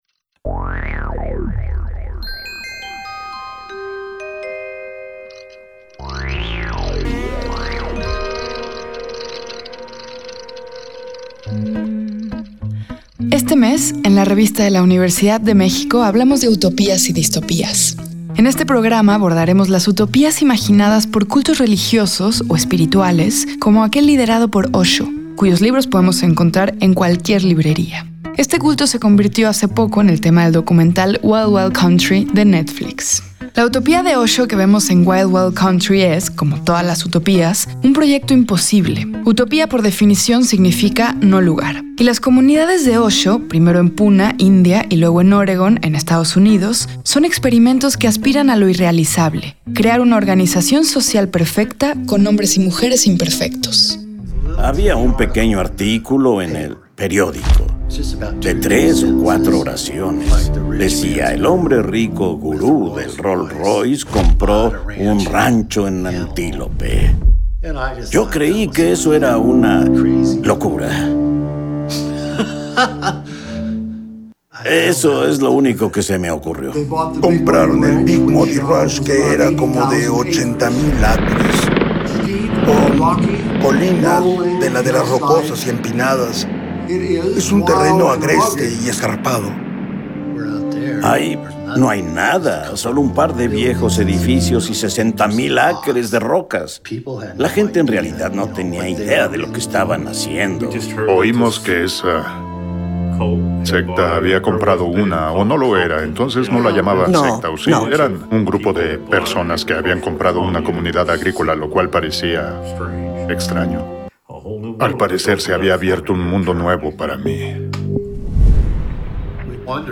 Guión radiofónico
Fue transmitido el jueves 29 de noviembre de 2018 por el 96.1 FM.